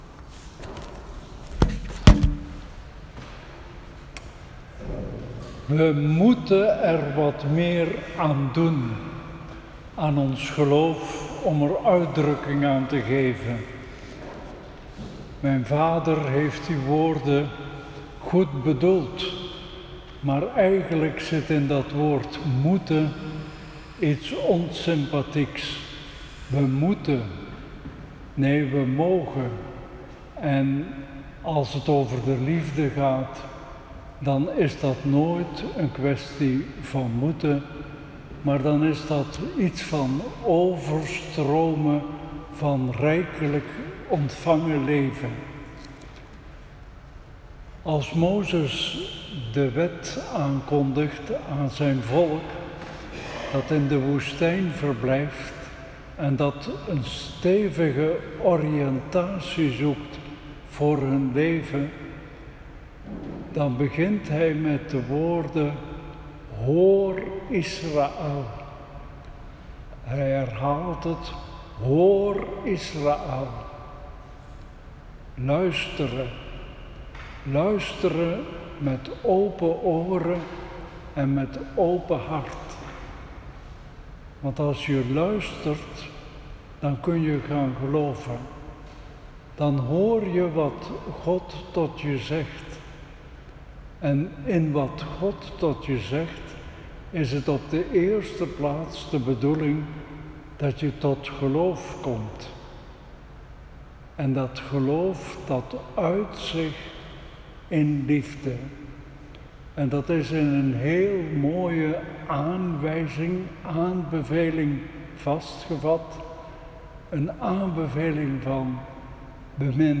Liturgie; Celebranten, vrijwilligers, gasten; Lezingen, Evangelie; Voorbeden; Inleidend woord, preek en slotwoord van Mons. Hurkmans; Fotoverslag
Preek-2.m4a